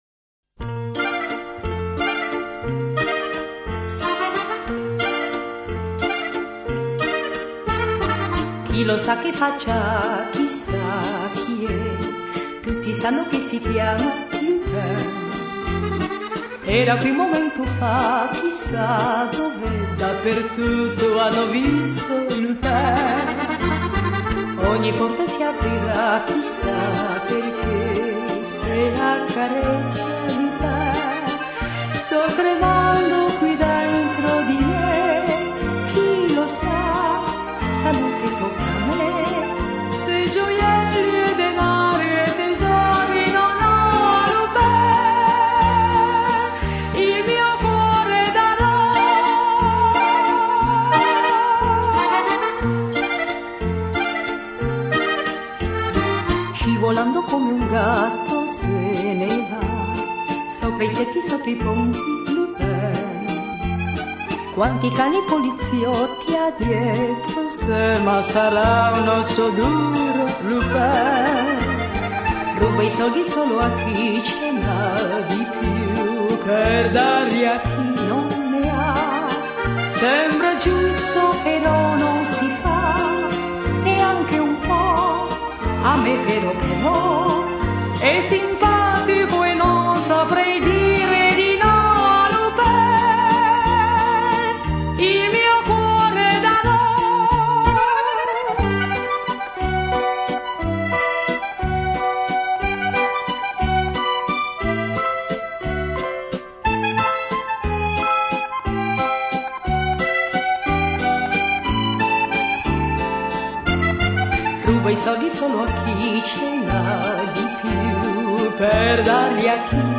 2° serie TV (opening song)